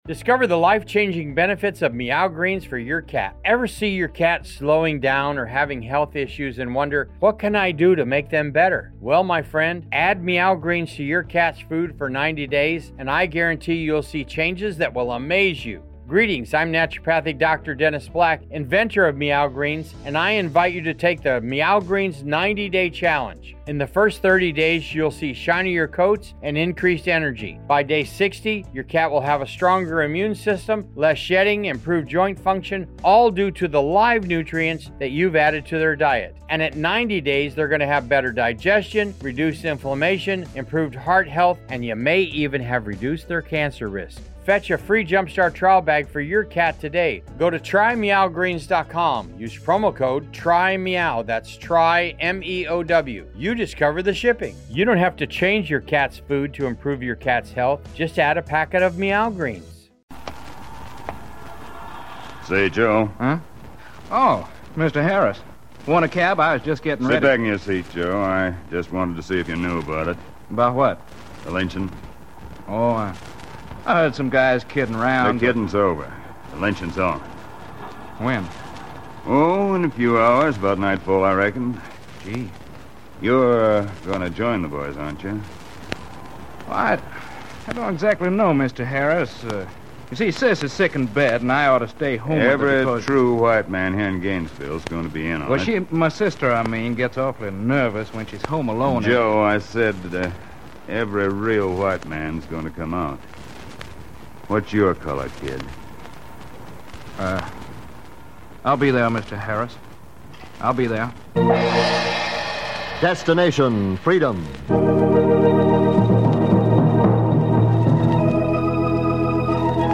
"A Garage in Gainesville" is an episode from the "Destination Freedom" series that aired on September 25, 1949. This series was known for its dramatizations of the lives and struggles of notable African Americans, highlighting their contributions to society and the fight against racial discrimination.